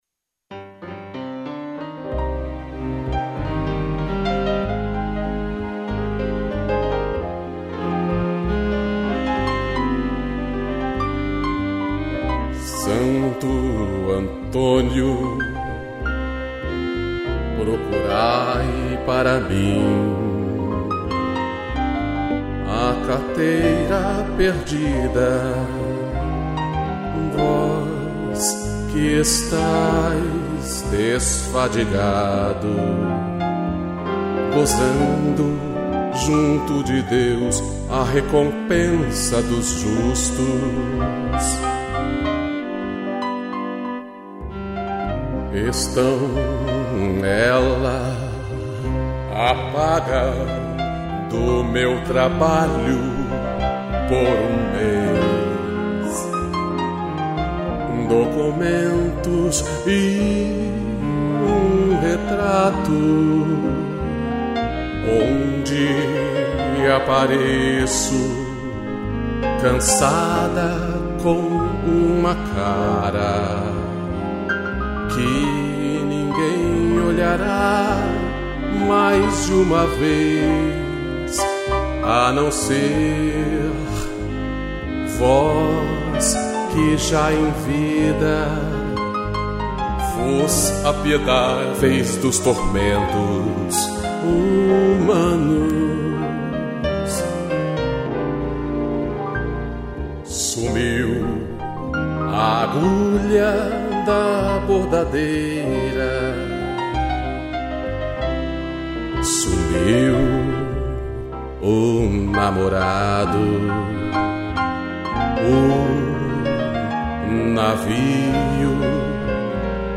piano e cello